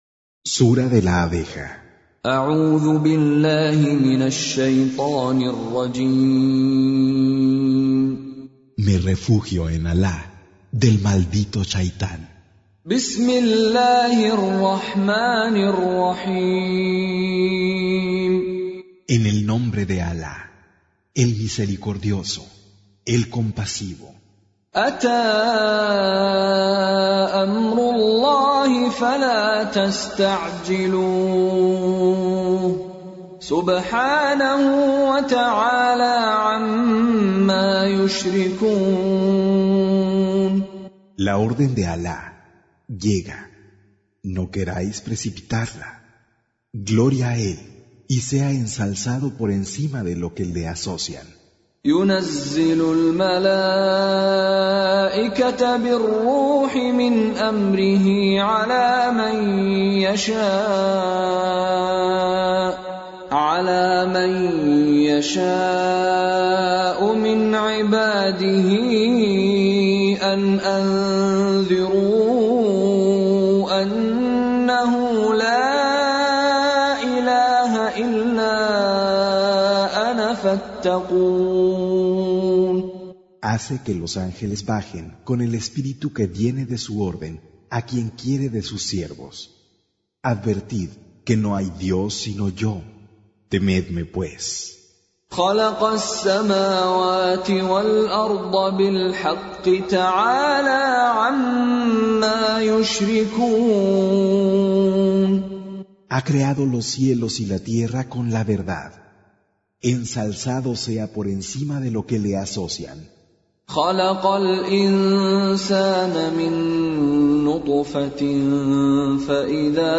Con Reciter Mishary Alafasi
Surah Sequence تتابع السورة Download Surah حمّل السورة Reciting Mutarjamah Translation Audio for 16. Surah An-Nahl سورة النحل N.B *Surah Includes Al-Basmalah Reciters Sequents تتابع التلاوات Reciters Repeats تكرار التلاوات